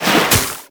Sfx_creature_penguin_dive_up_to_land_short_01.ogg